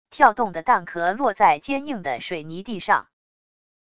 跳动的弹壳落在坚硬的水泥地上wav音频